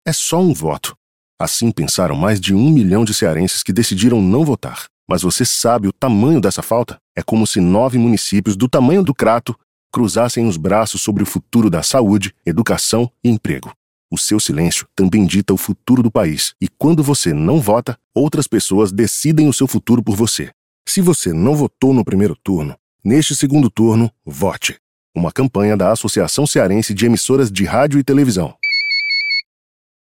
É importante a divulgação maciça do spot anexo, atingindo os eleitores em todas as regiões do Estado.